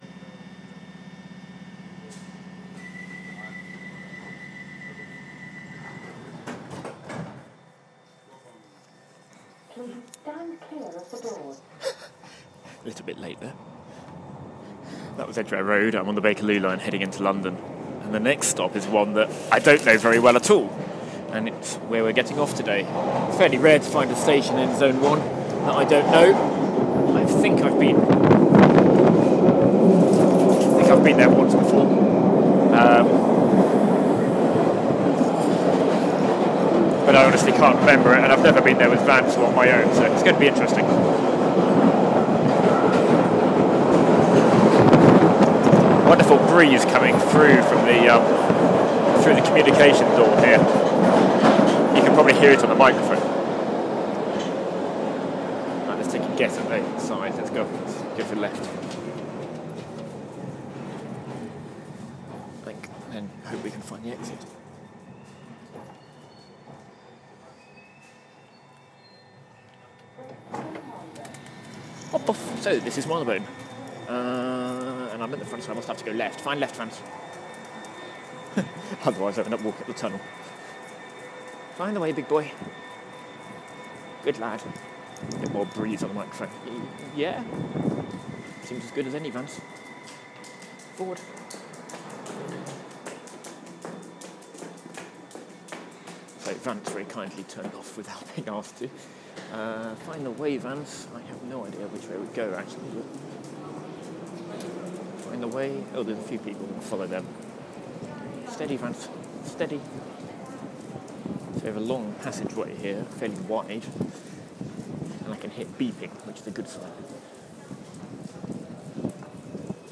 Marylebone Station for the first tine